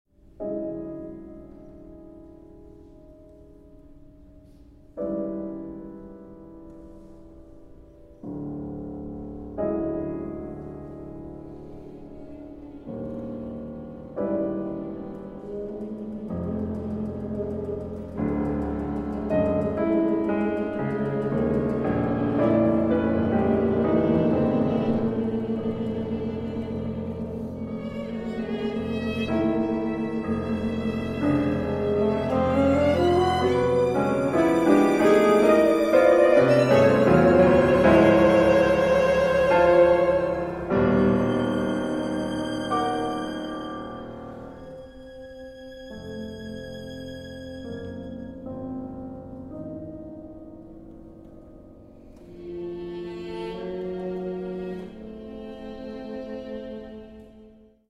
Instrumentation: violin, alto saxophone, piano